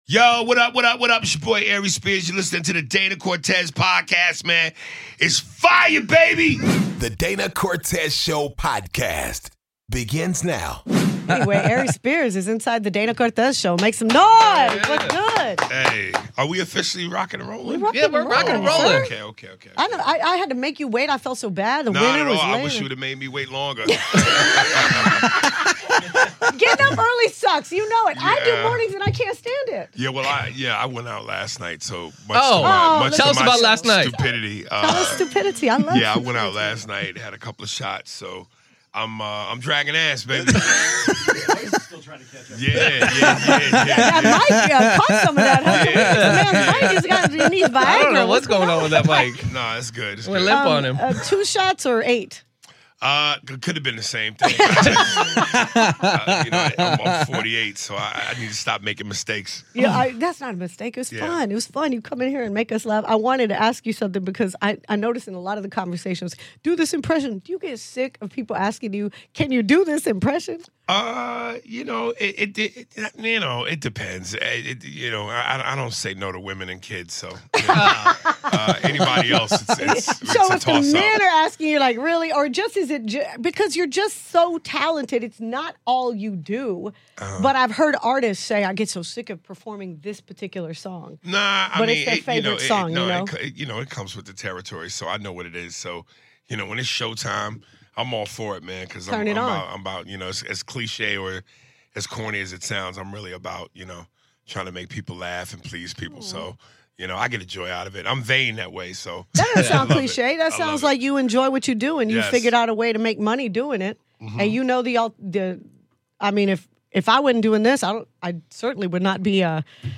DCS Interviews Comedian Aries Spears